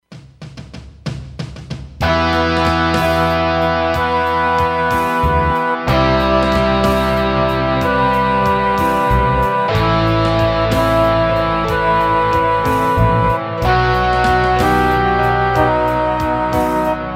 Instrumental mp3 Song Track